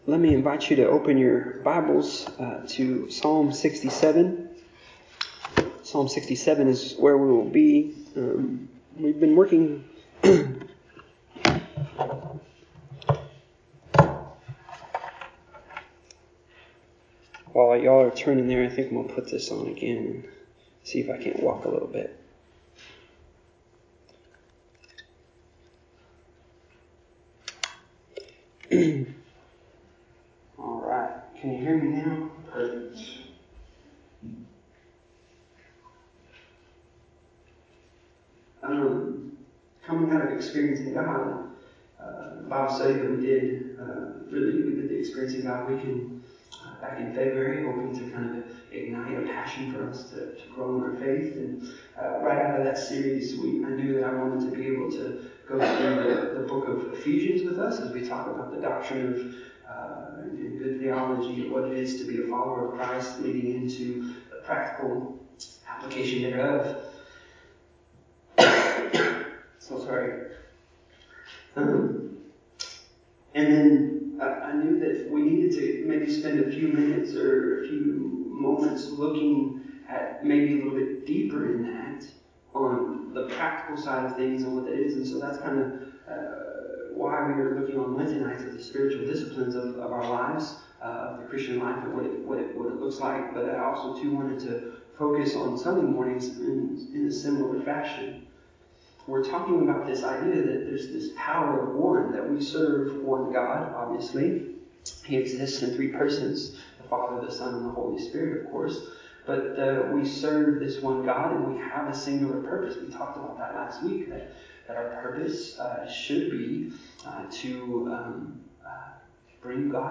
The audio recording is from the in-person gathering – recorded live and uploaded at a later date.